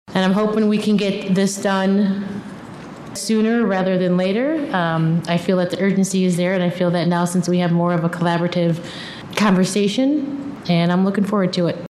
Commissioner Qianna Decker says the need is real and urgent.